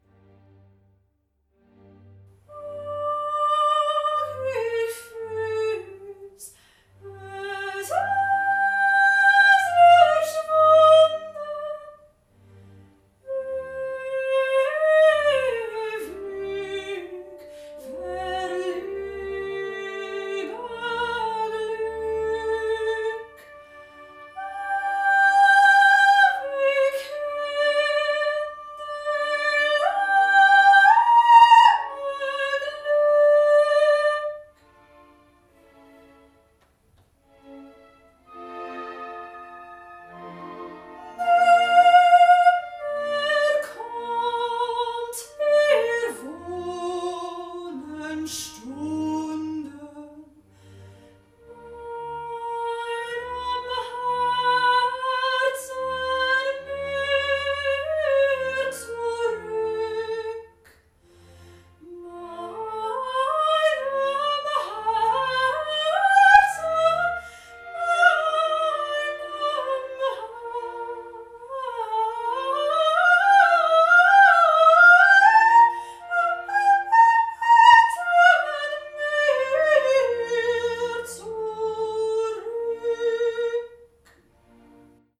Here I’ve provided some voice recordings of me singing in different styles.
The first is classical, which I am most confident in:
Classical Example